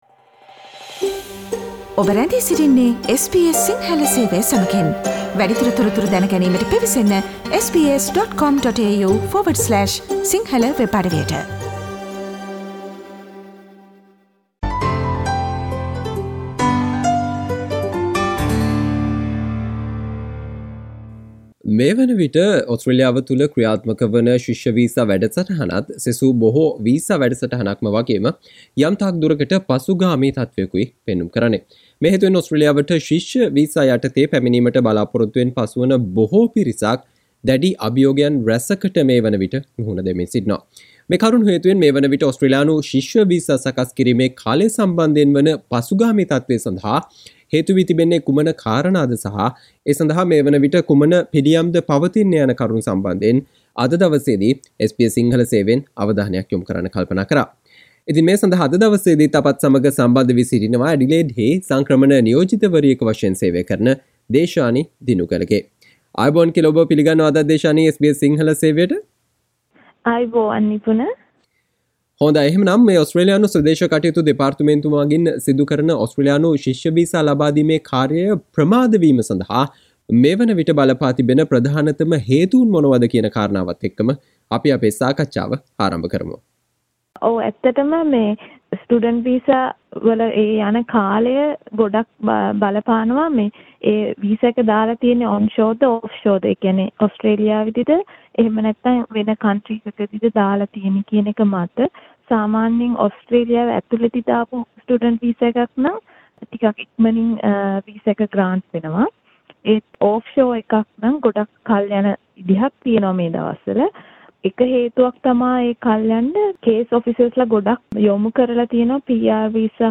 මේ වනවිට ඕස්ට්‍රේලියානු ජාත්‍යන්තර ශිෂ්‍ය වීසා සකස් කිරීමට කාලය ගතවීම පිළිබඳව සහ ඒ සම්බන්ධයෙන් ඕස්ට්‍රේලියාවට ශිෂ්‍ය වීසා යටතේ පැමිණීමට සිටින පිරිස සිදු කලයුතු දේ පිළිබඳව SBS සිංහල සේවය සිදු කල සාකච්චාවට සවන්දෙන්න